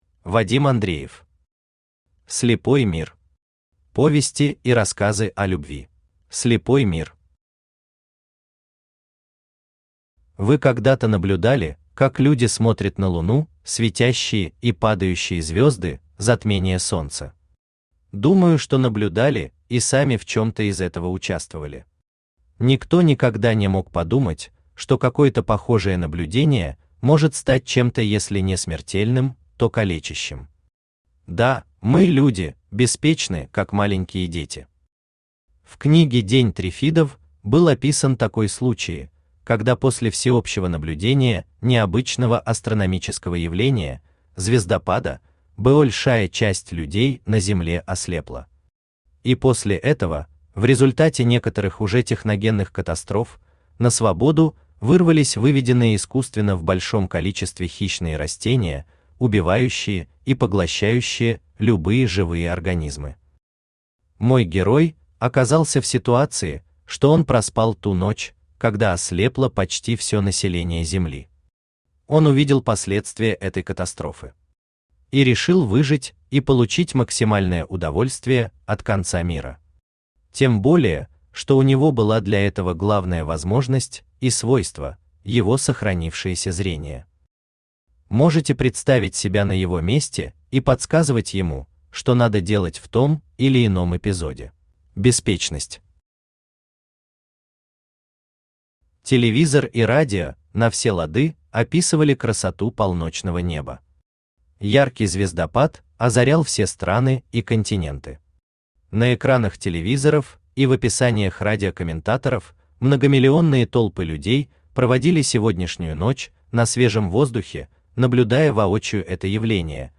Аудиокнига Слепой мир. Повести и рассказы о любви | Библиотека аудиокниг
Повести и рассказы о любви Автор Вадим Андреев Читает аудиокнигу Авточтец ЛитРес.